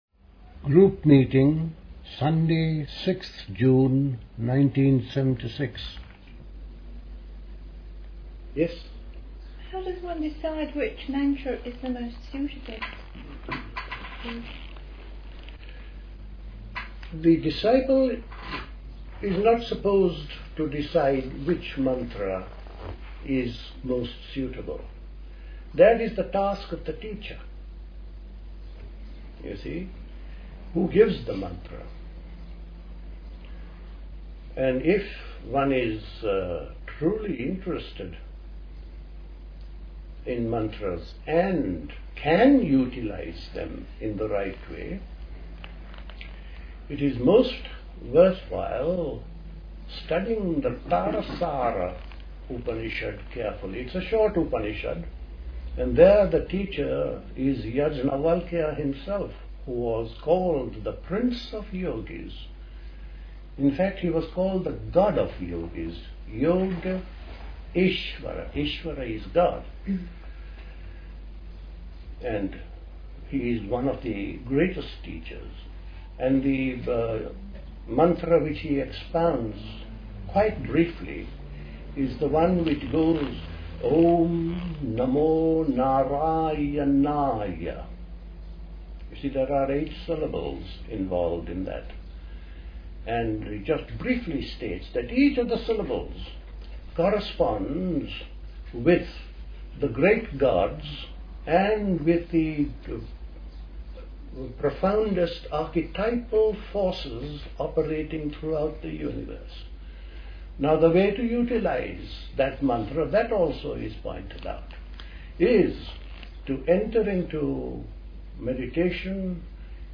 A talk
at Dilkusha, Forest Hill, London on 6th June 1976